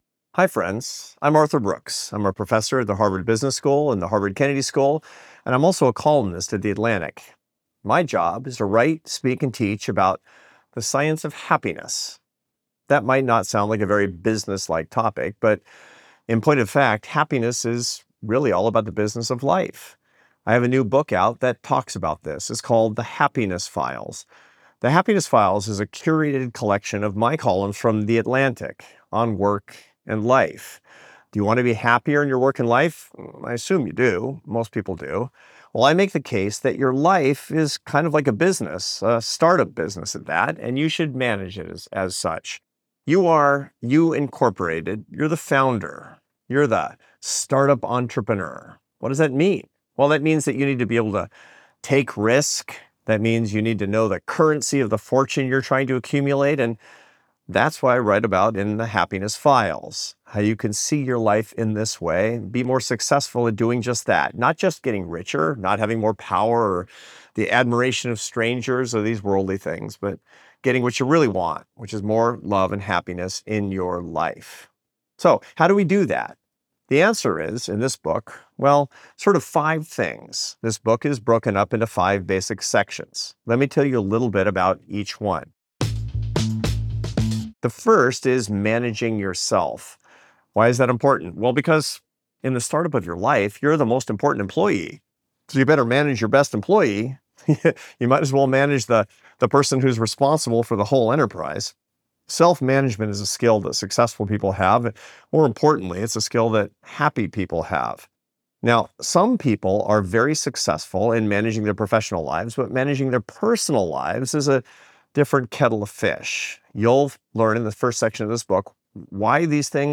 Listen to the audio version—read by Arthur himself—below, or in the Next Big Idea App.